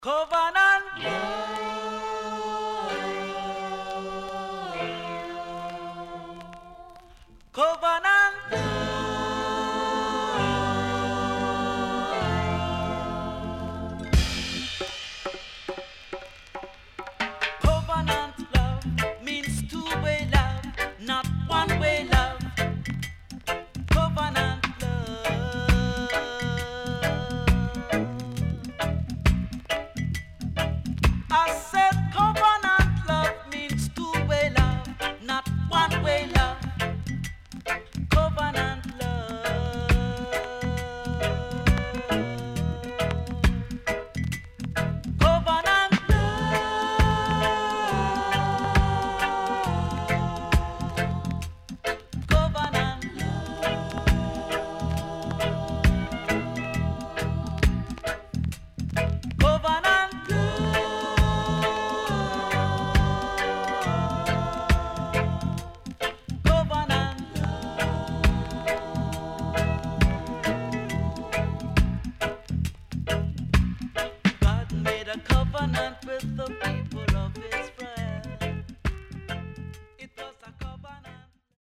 HOME > Back Order [VINTAGE 7inch]  >  KILLER & DEEP
CONDITION SIDE A:VG(OK)〜VG+
SIDE A:序盤小傷により数発プチノイズ入りますが落ち着きます。